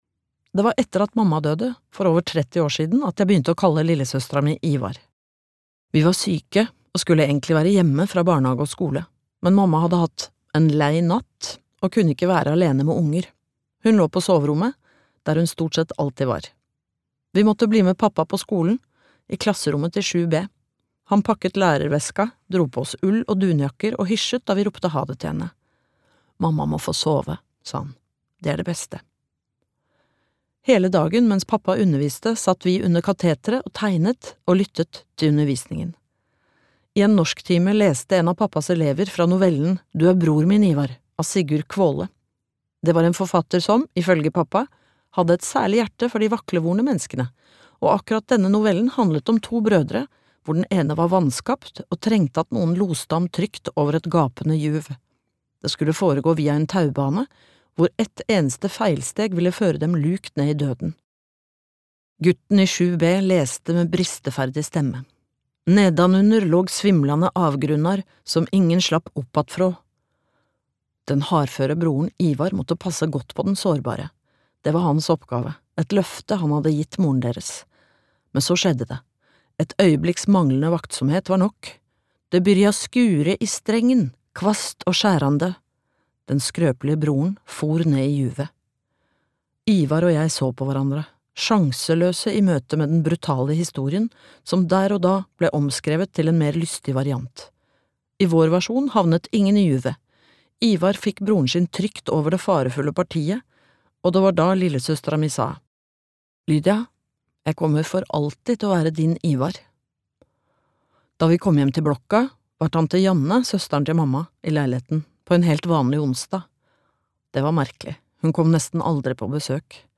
Hjerteråte (lydbok) av Kathinka Steenstrup